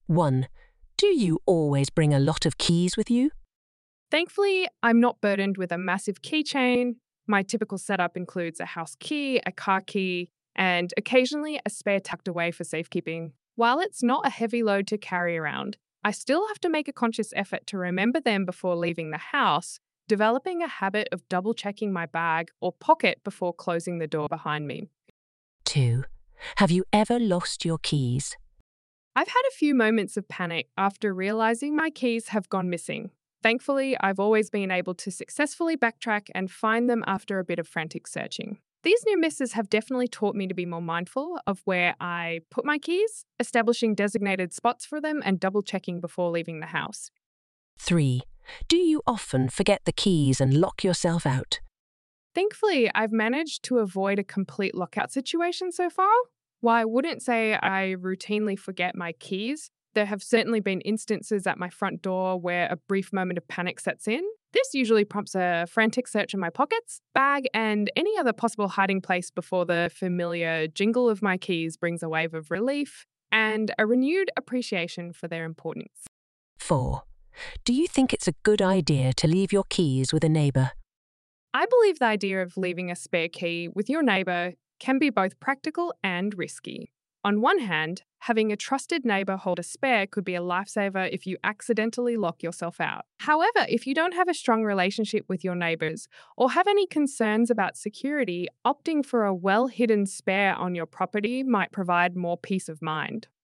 Trong bài viết này, Mc IELTS đã chuẩn bị sẵn cho bạn những câu hỏi và câu trả lời mẫu, kèm audio để bạn dễ luyện tập và bắt nhịp giọng nói tự nhiên.